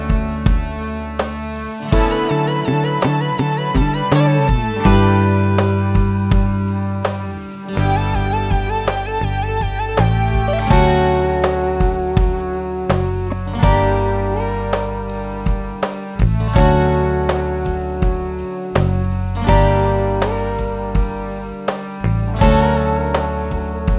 D Major Minus Sax and Clarinet Pop (1970s) 4:15 Buy £1.50